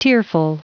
Prononciation du mot tearful en anglais (fichier audio)
Prononciation du mot : tearful